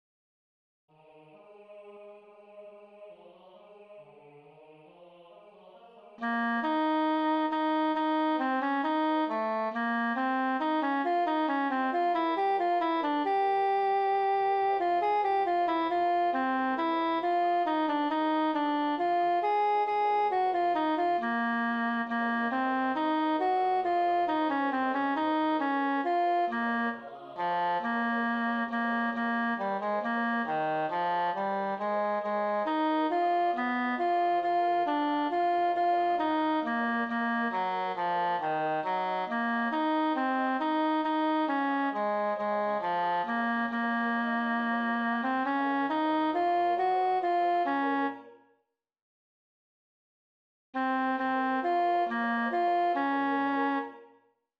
54 Chor „Herr, wenn die stolzen“ Teil A1/A2        A       B